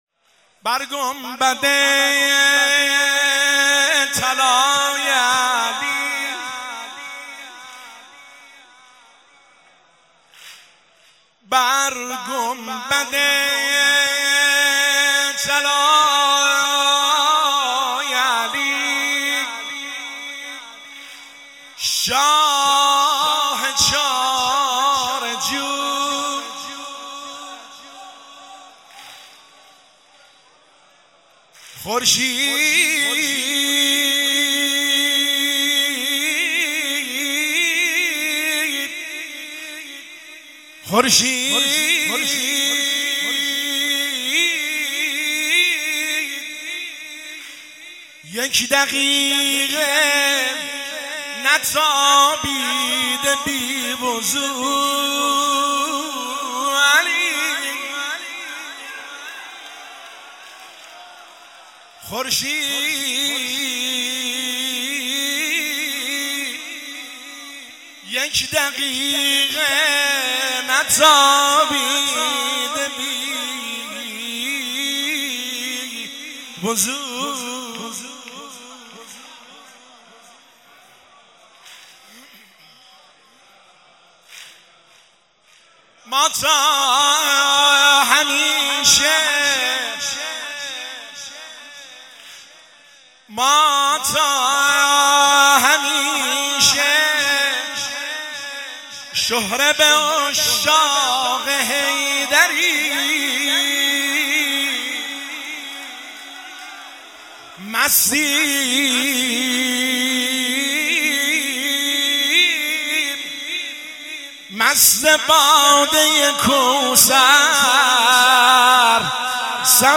هیئت بین الحرمین طهران